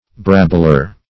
Search Result for " brabbler" : The Collaborative International Dictionary of English v.0.48: Brabbler \Brab"bler\, n. A clamorous, quarrelsome, noisy fellow; a wrangler.